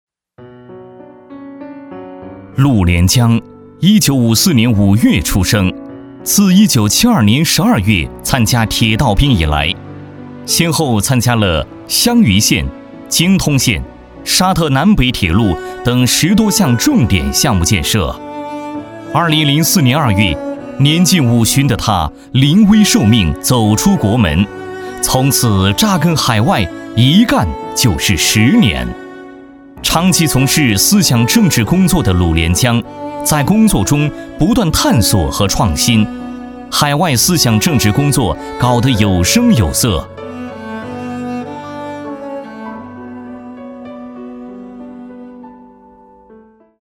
男国36_专题_人物_铁道兵人物_抒情.mp3